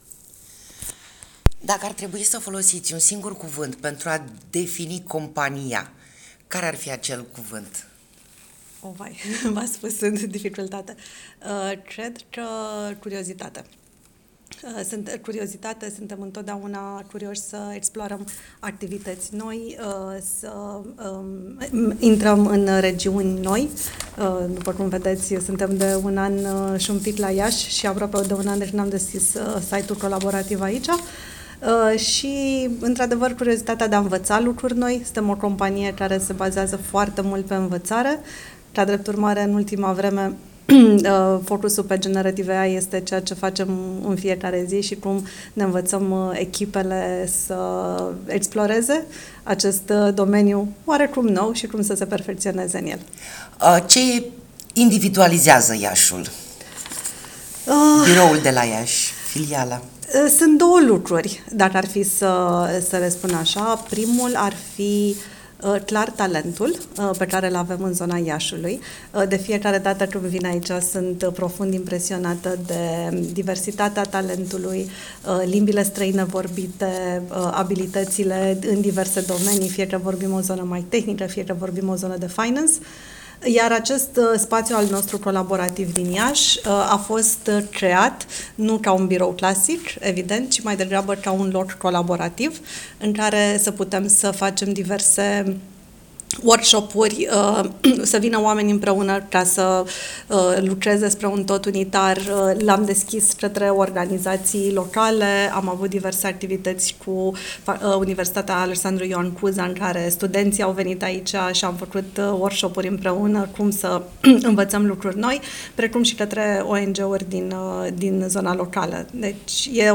Interviu-1.wav